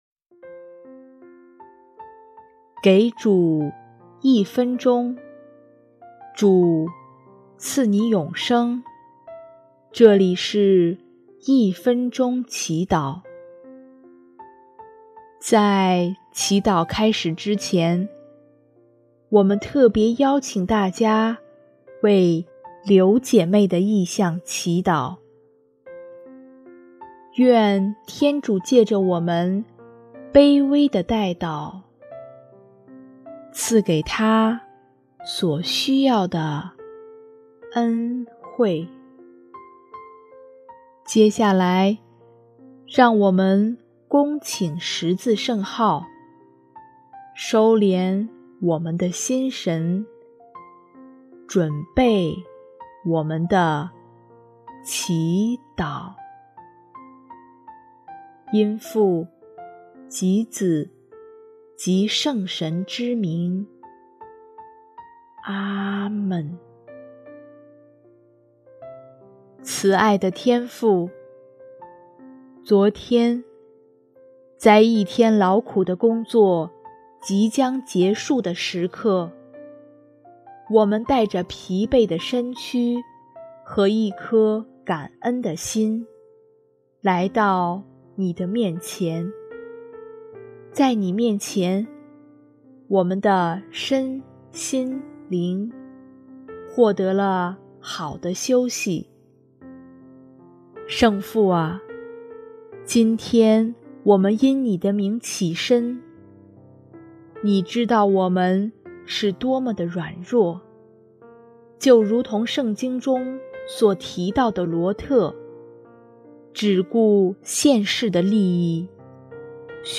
【一分钟祈祷】|6月27日 放眼注目那永恒的财富